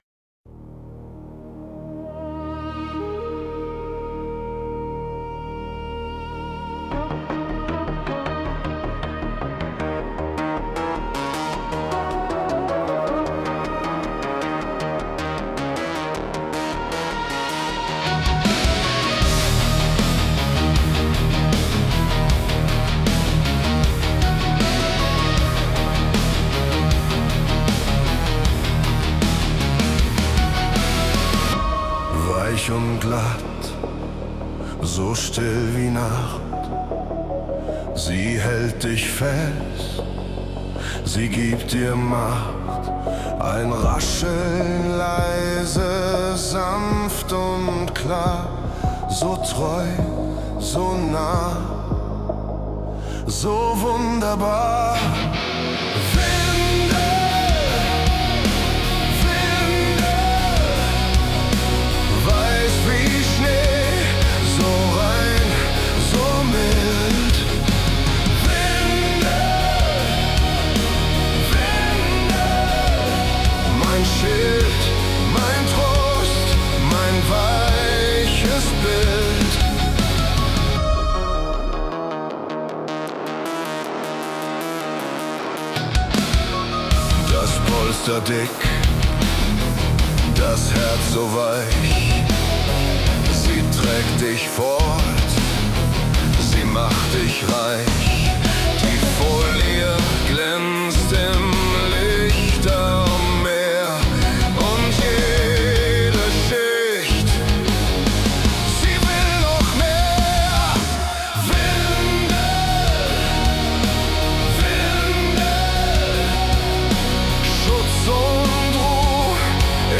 schwer, rhythmisch und mit einem Hauch Ehrfurcht.
Vocal: KI